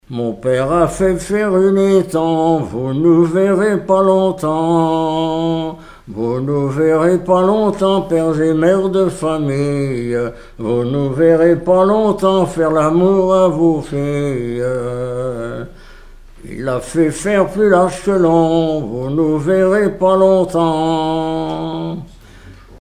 collectif de chanteurs du canton
Pièce musicale inédite